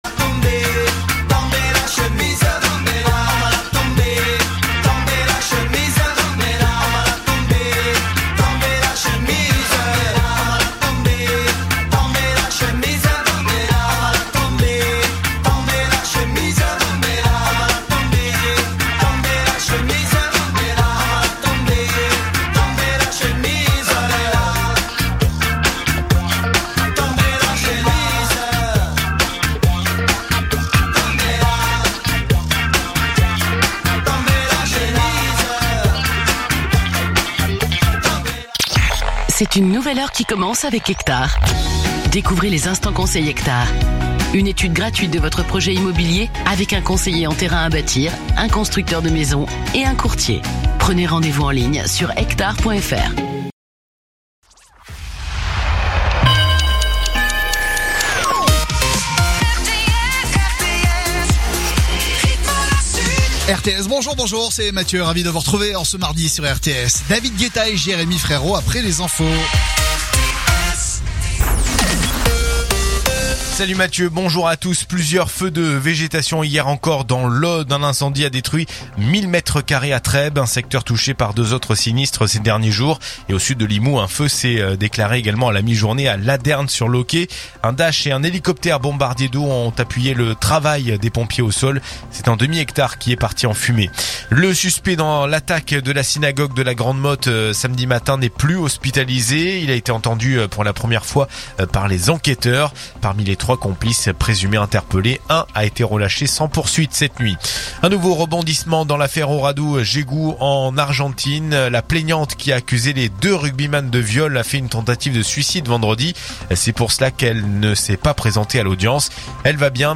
info_narbonne_toulouse_123.mp3